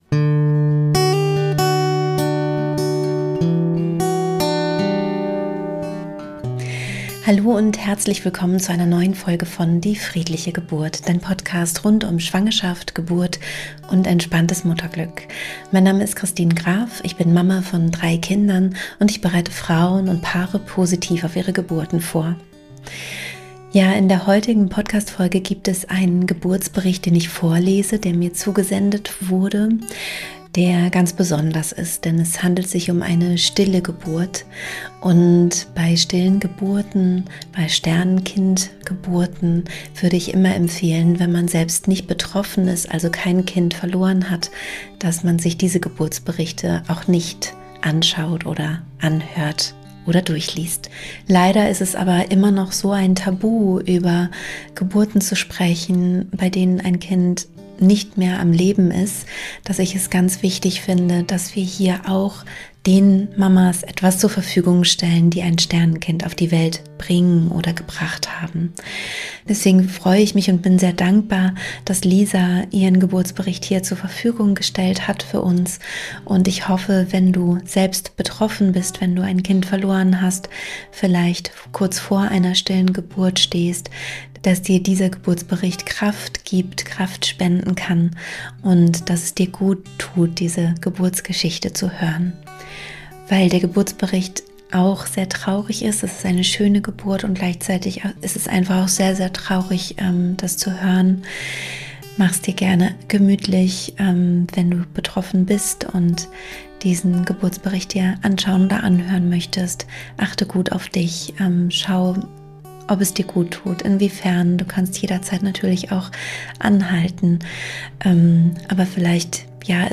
In diesem Geburtsbericht, den ich vorlese